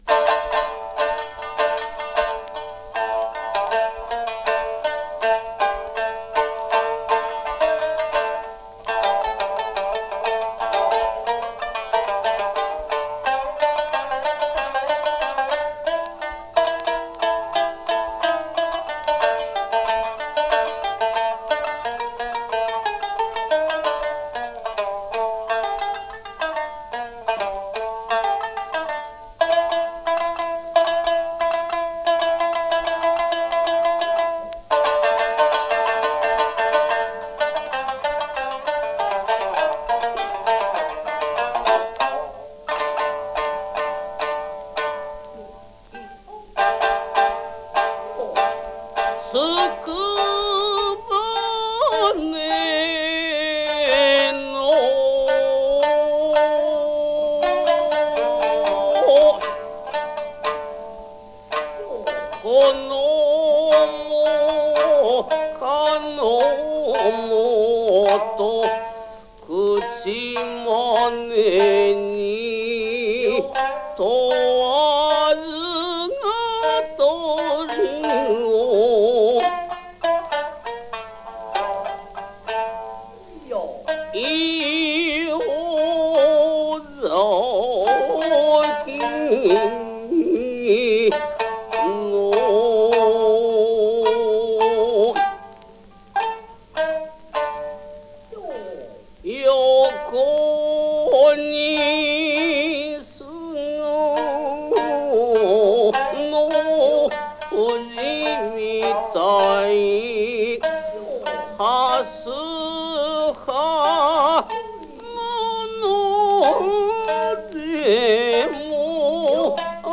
台本でもほんの数行の歌詞ですが、ここの部分の三味線の節がとっても素敵な演目です。